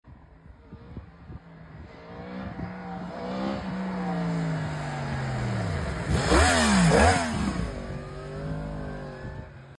Yamaha MT 10 2025 Flyby Sound sound effects free download
Yamaha MT-10 2025 Flyby Sound Brutal💥💨